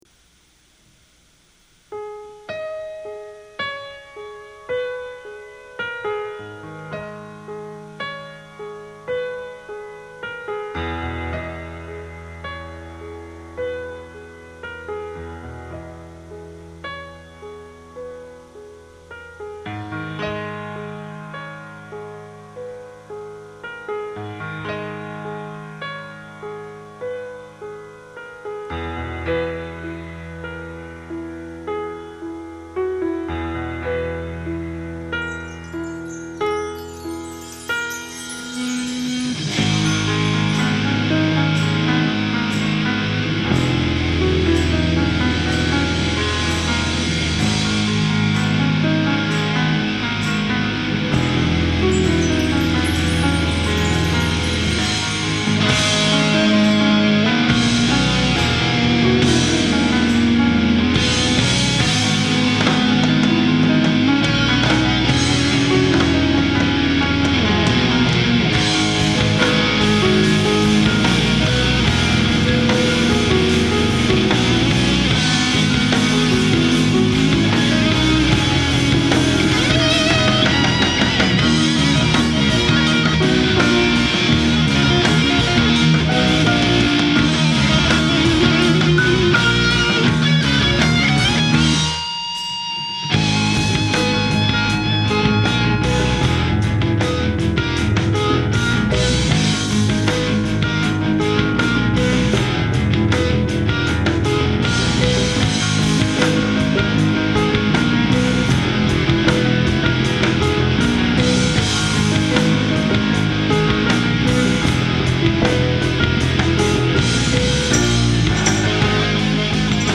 rough mix after 6 days